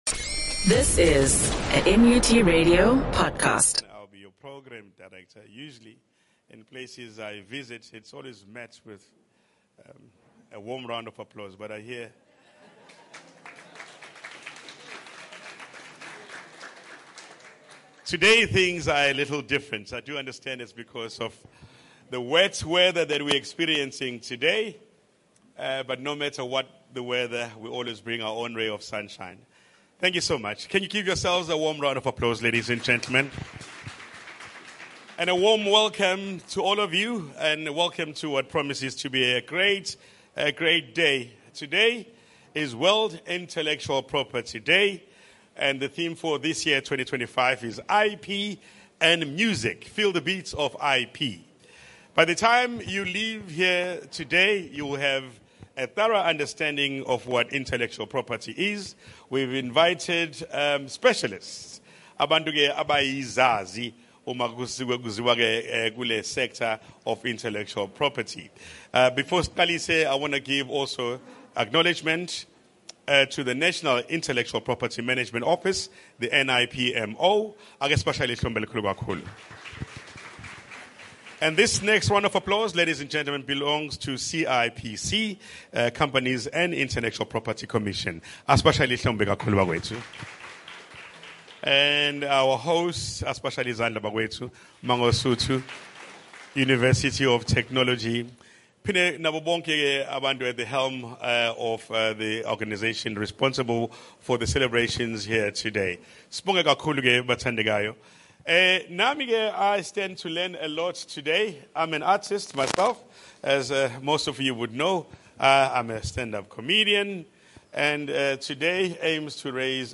The Mangosuthu University oF Technology celebrated world IP day 2025 in joint partnership with CIPC, NIPMO, TIA,DTIC, UKZN,DUT,DFO. The event was held at the Mangosuthu University of Technology Student center where students came to learn more about the industry and IP different experts came to share their knowledge.